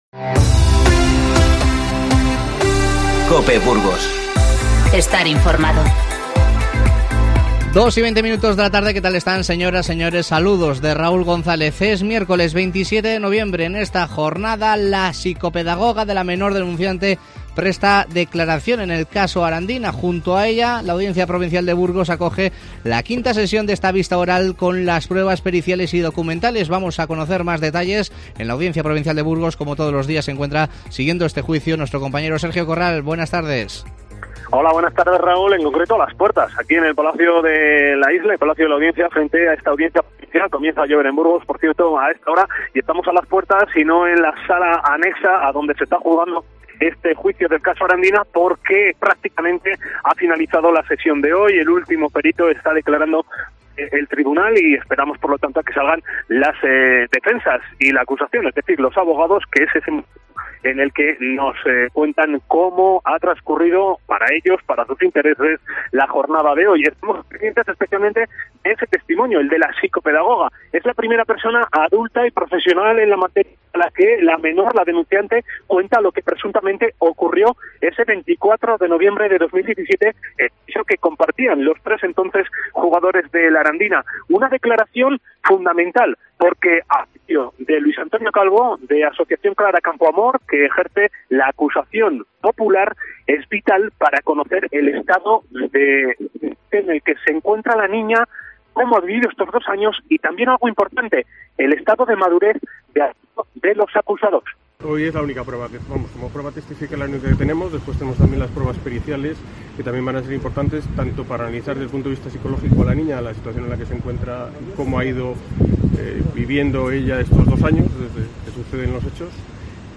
INFORMATIVO Mediodía 27-11-19